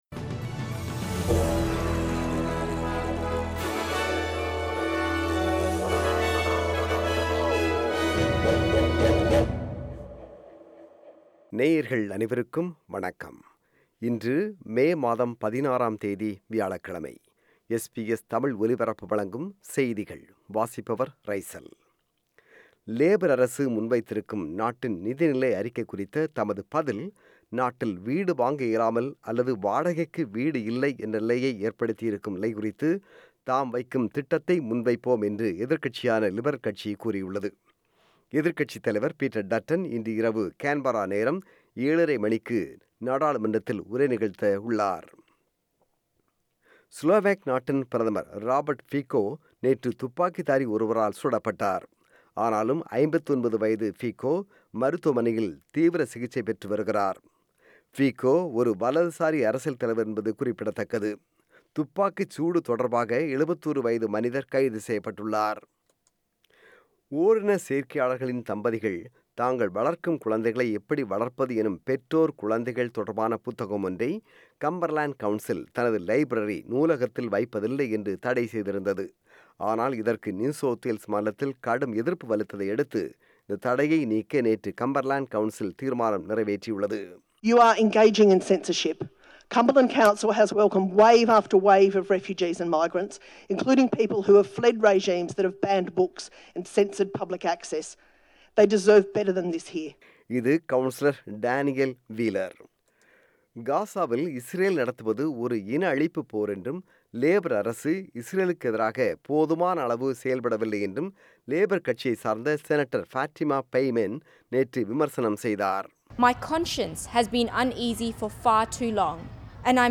செய்திகள்